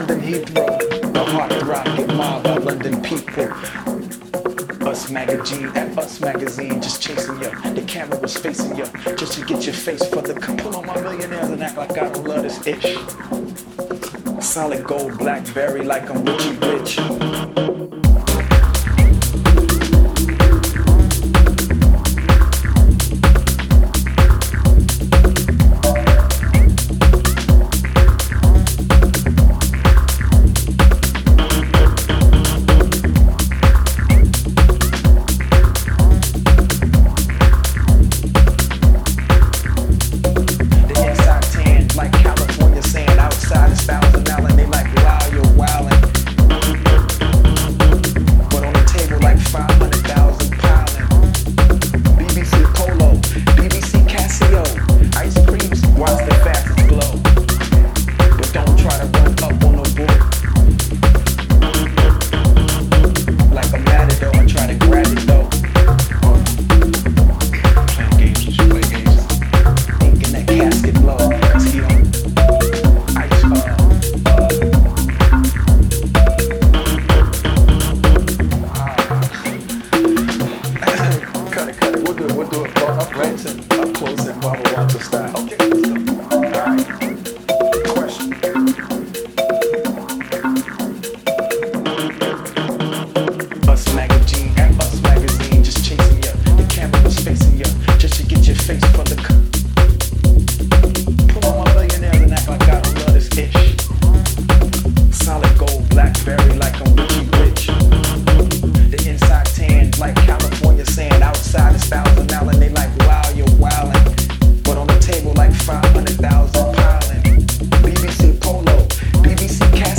Styl: House, Techno, Minimal